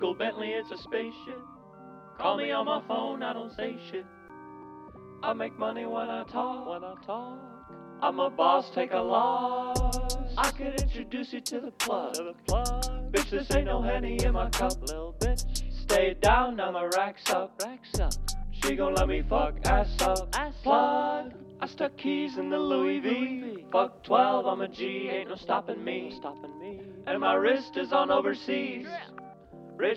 Плавные вокальные партии и чувственный бит
Саксофон и лёгкий свинг
Глубокий соул-вокал и плотные хоровые партии
Жанр: R&B / Джаз / Соул